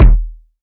Kicks
KICK.17.NEPT.wav